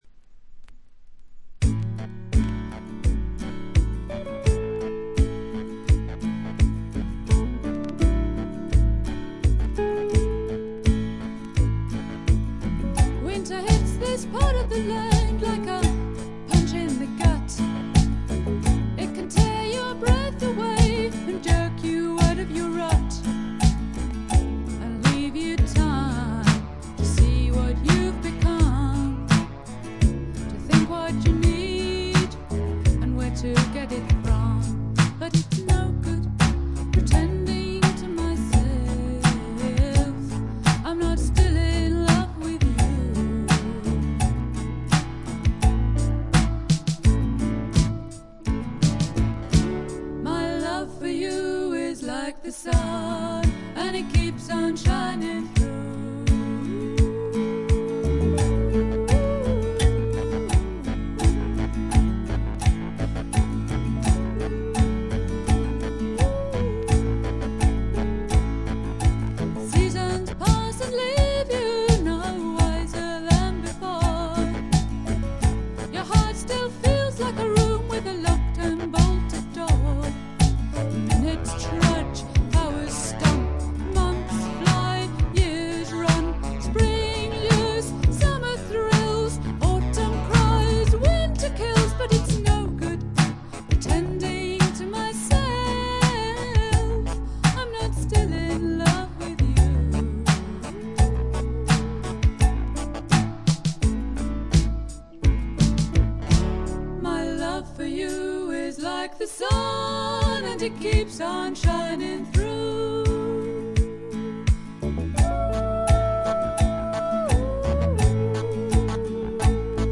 ホーム > レコード：英国 SSW / フォークロック
ところどころで軽いチリプチ程度。
少しざらついた美声がとても心地よいです。
試聴曲は現品からの取り込み音源です。
vocals, guitars, banjo